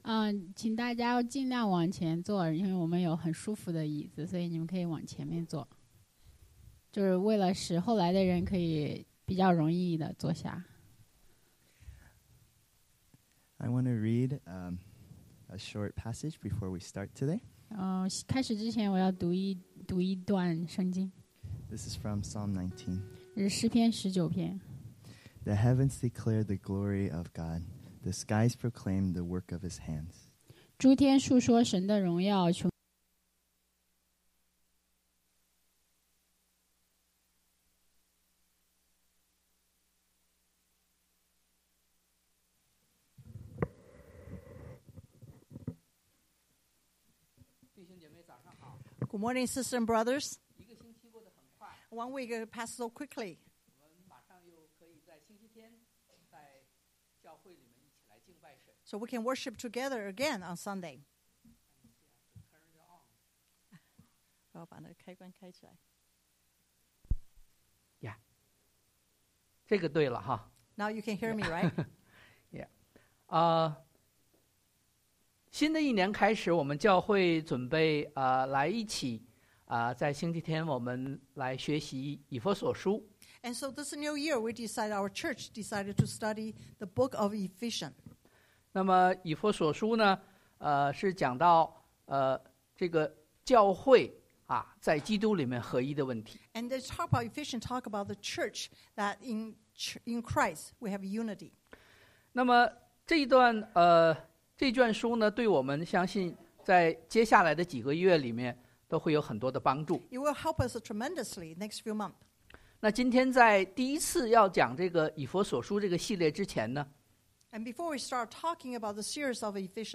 Rev 2 Service Type: Sunday AM Bible Text